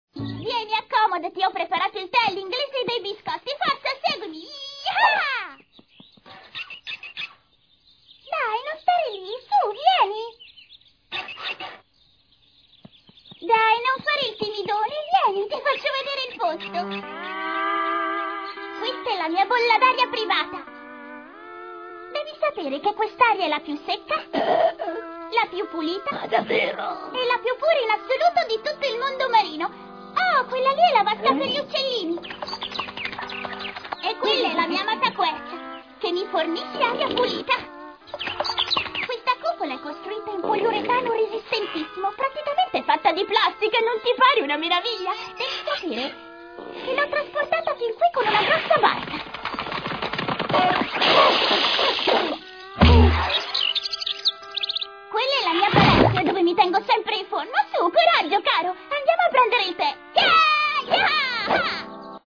dal cartone animato "SpongeBob", in cui doppia Sandy Cheeks.